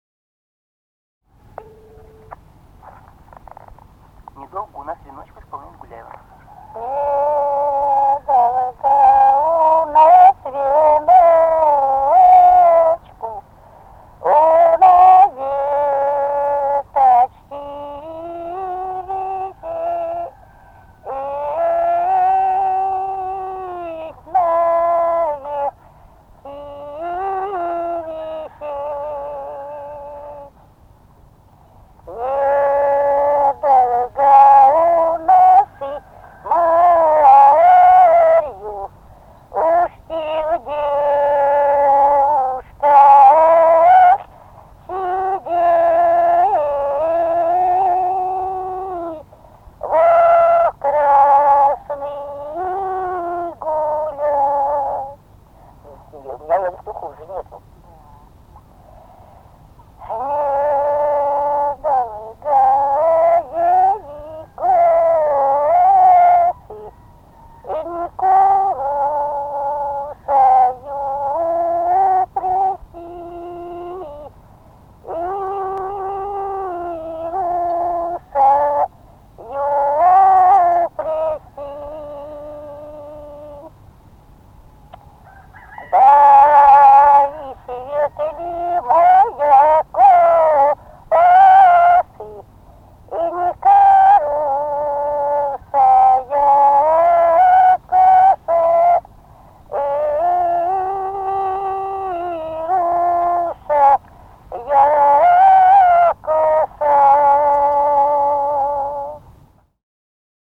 Русские народные песни Владимирской области 14. Недолго у нас веночку на веточке висеть (свадебная) с. Смолино Ковровского района Владимирской области.